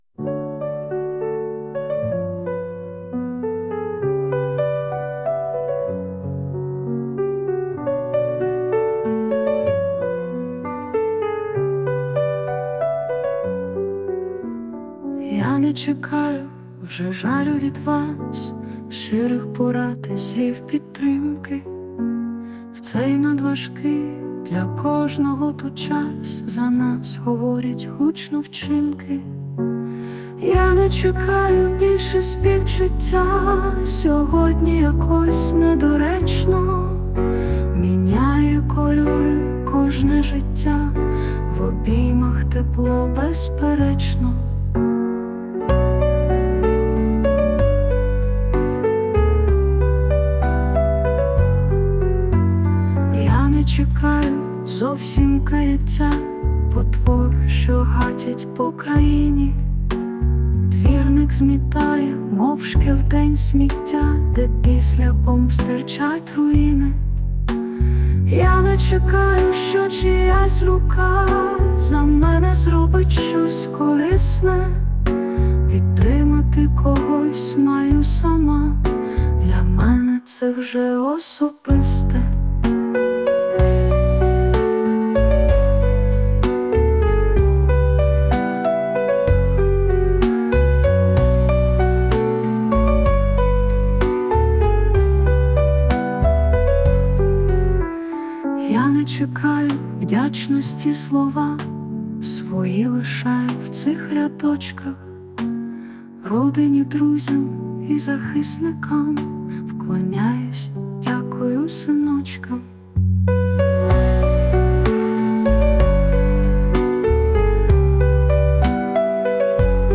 Музичний супровід створено за допомогою  SUNO AI
СТИЛЬОВІ ЖАНРИ: Ліричний
Зворушлива пісня...до сліз... frown flo11 flo11 flo11